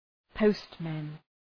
Προφορά
{‘pəʋstmen}
postmen.mp3